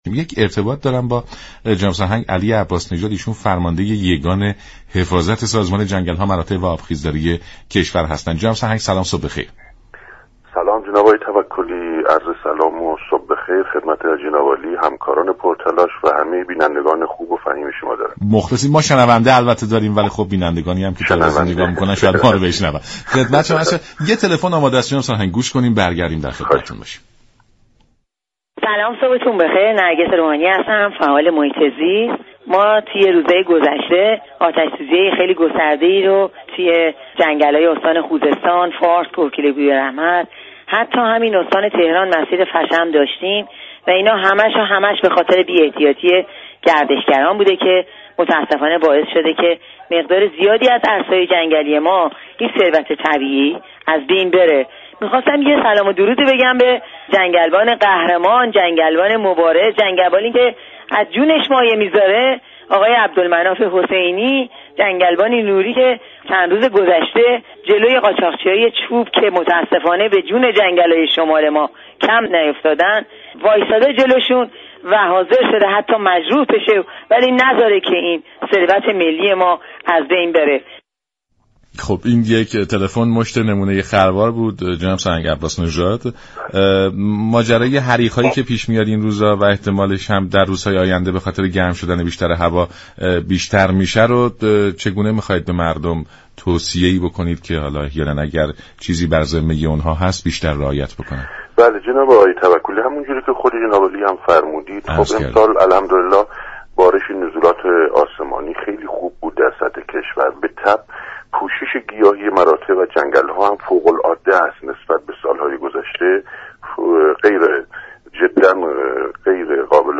جناب سرهنگ علی عباس نژاد فرمانده یگان حفاظت سازمان جنگل ها و مراتع و آبخیزداری كشور در گفت و گو با برنامه "سلام صبح بخیر" به آتش سوزی های اخیر در جنگل های كشور اشاره كرد و با بیان اینكه هر ساله شاهد این اتفاقات هستیم، افزود: علی رغم همه هشدارهای انجام شده باید گفت متاسفانه 80 درصد از آتش سوزی های اخیر به دلیل بی توجهی ها و برخی سهل انگاری ها بوده است.
برنامه سلام صبح بخیر شنبه تا پنج شنبه هر هفته ساعت 6:35 از رادیو ایران پخش می شود.